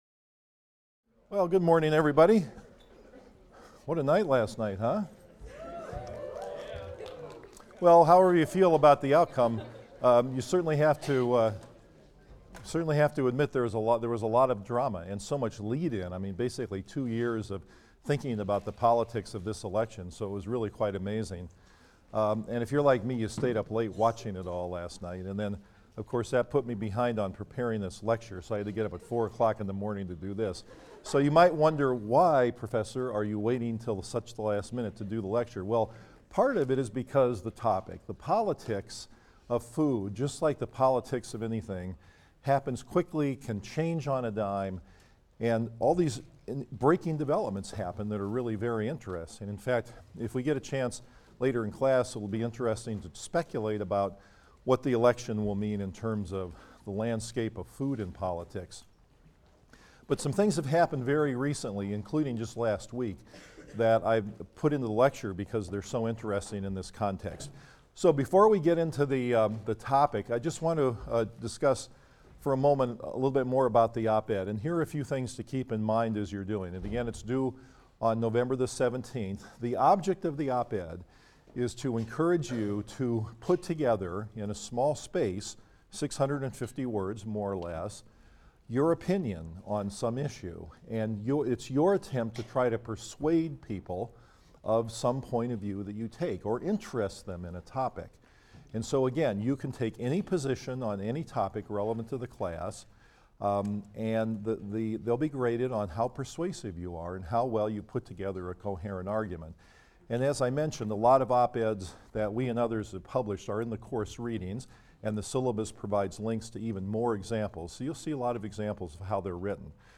PSYC 123 - Lecture 18 - The Politics of Food II: The Issues, the Fights and Who Controls the Frame | Open Yale Courses